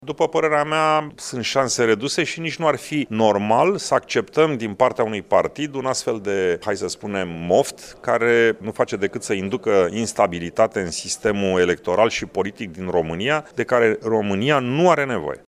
Declaraţia aparţine copreşedintelui ALDE și președinte al Senatului, Călin Popescu Tăriceanu, care a mai spus astăzi, la Iaşi, că în urmă cu un an, când era primul în sondaje, PNL a votat alături de PSD schimbarea sistemului de vot.